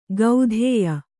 ♪ gaudhēya